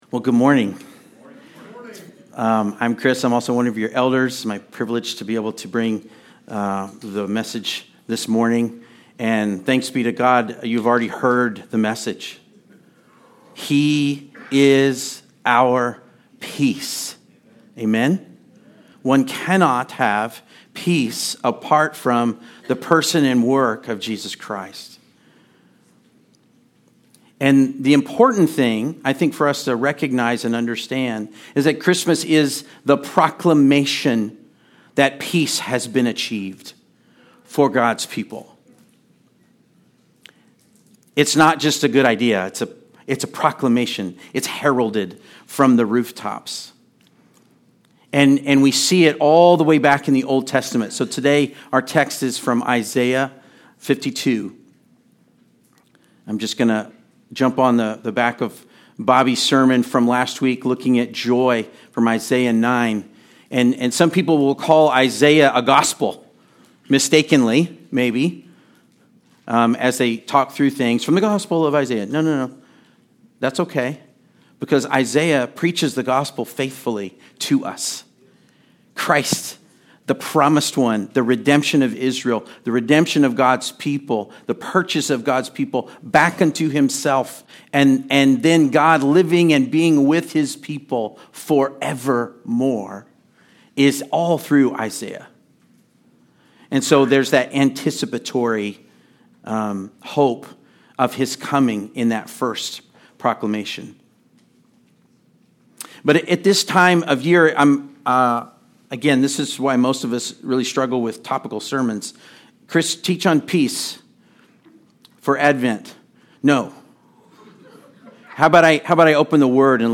Passage: Isaiah 52:6-10 Service Type: Sunday Service